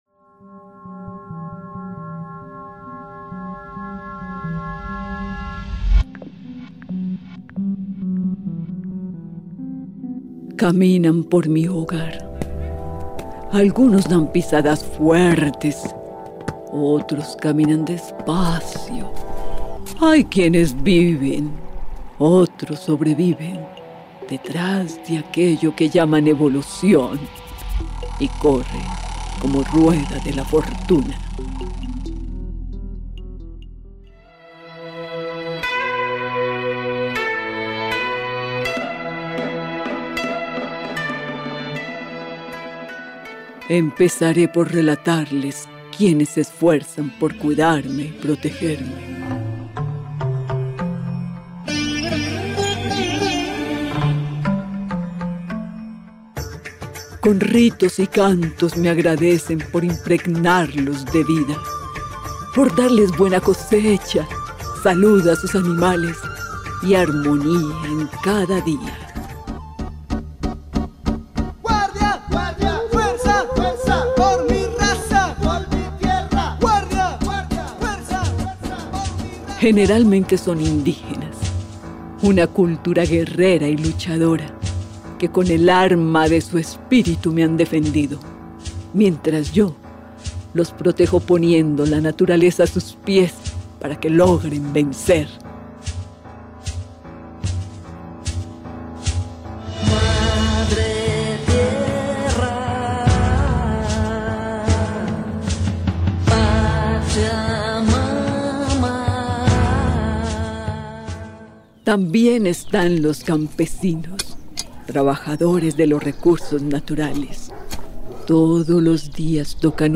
NOTA: Los Podcast de Referencia “Pieza especial” hacen parte del segundo ejercicio intermedio de los radialistas del semillero “El vuelo del colibrí”, aquí, más que reflexionar alguno de los momentos hallados en la investigación, se trata de construir un audio totalmente creativo, a partir del sonido y la narrativa, que ilustre alguno de los conceptos trabajados en el proceso.